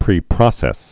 (prē-prŏsĕs, -prōsĕs)